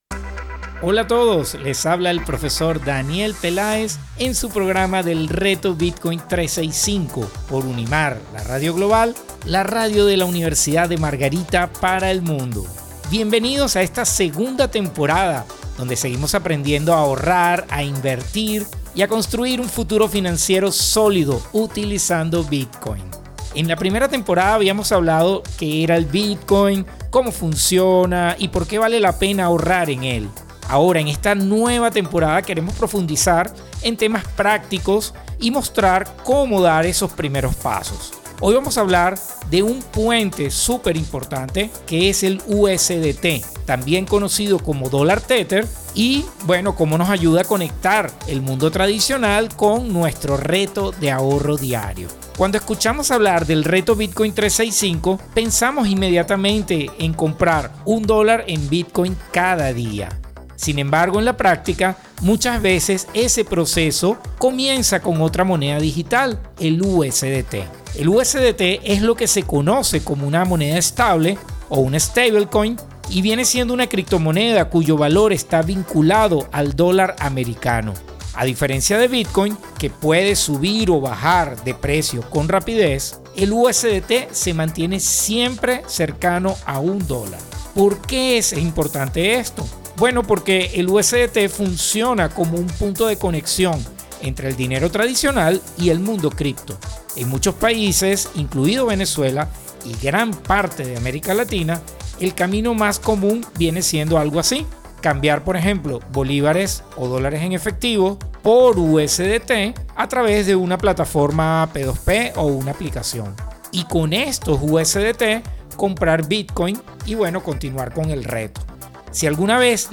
A través de entrevistas, testimonios y cápsulas informativas, los oyentes aprenderán estrategias prácticas para invertir de manera sostenida en Bitcoin utilizando el método de Dollar Cost Averaging (DCA).